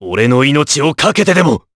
Clause_ice-Vox_Skill2_jp.wav